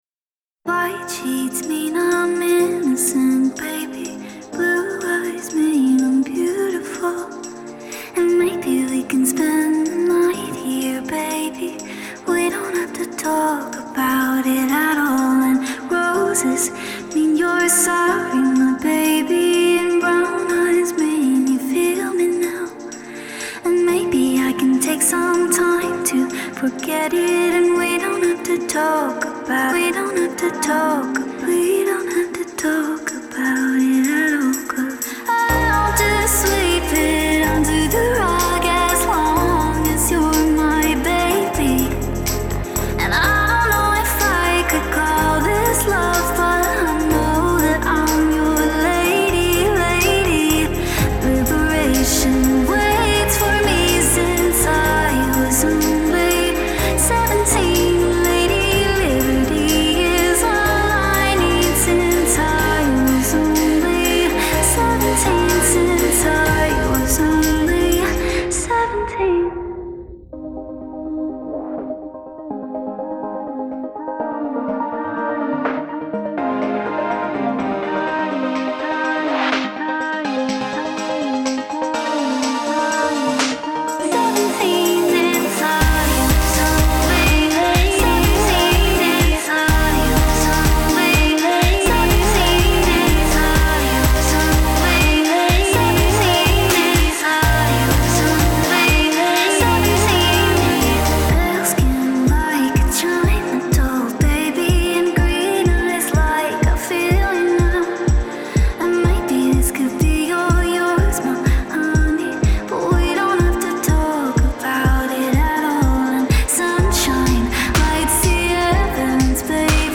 Genre: Electronic, Pop